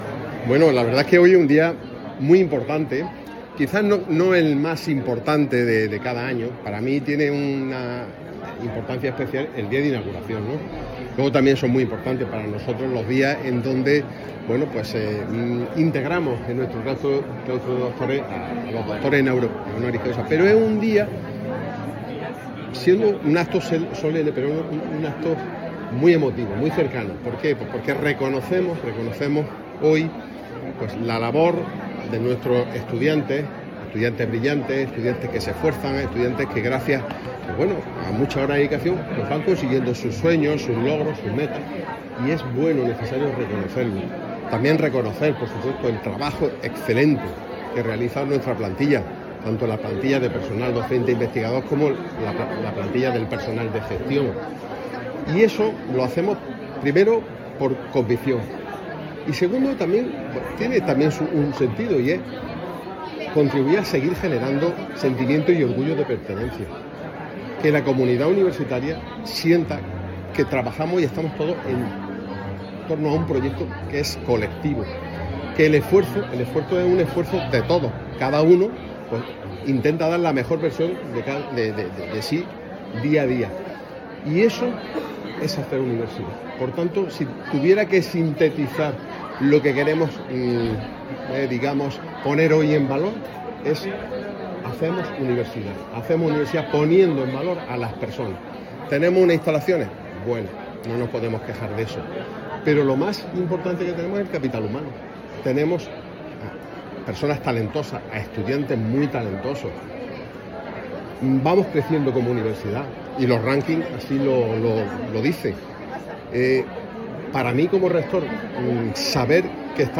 declaraciones_Rector_DíaUniversidad.mp3